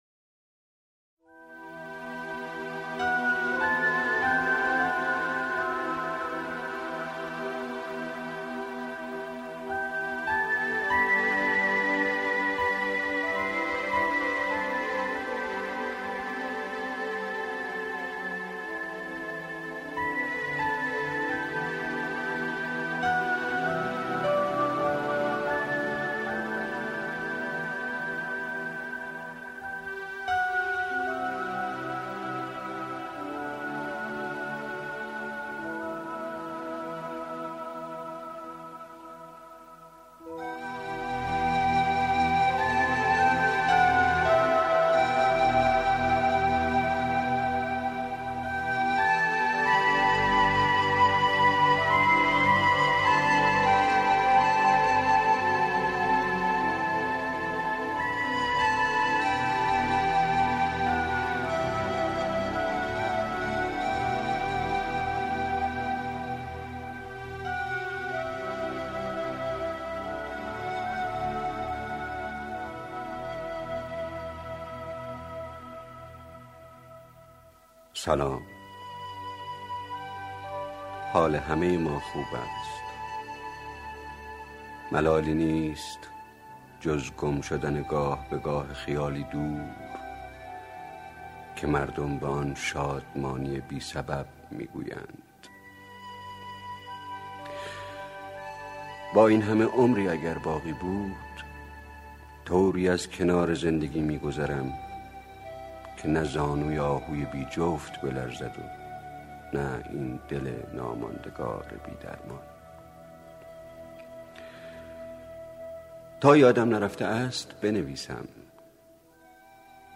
با صدای آرامش بخش استاد شکیبایی ♥